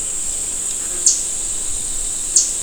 "Reinita Pechidorada"
"Northern Parula"
Parula americana
reinita-pechidorada.wav